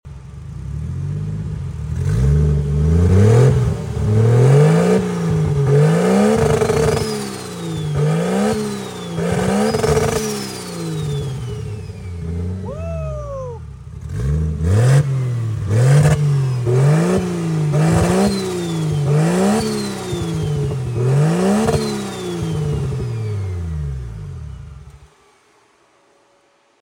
1jz Sounds Sound Effects Free Download